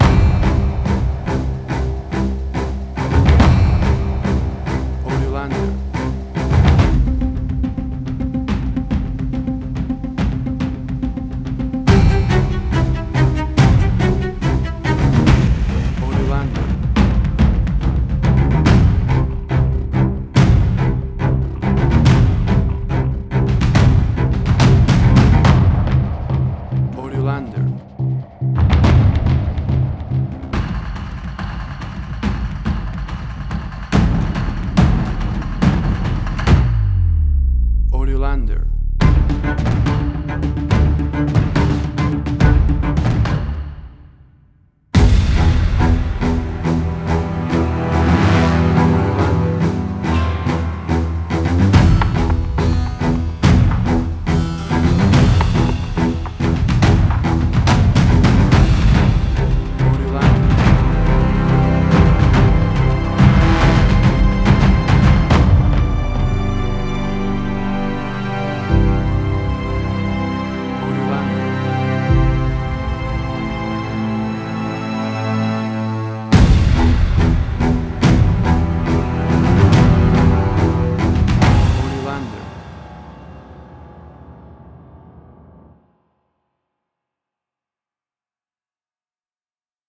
Original Gangster Score Mob town cinematic.
WAV Sample Rate: 16-Bit stereo, 44.1 kHz
Tempo (BPM): 142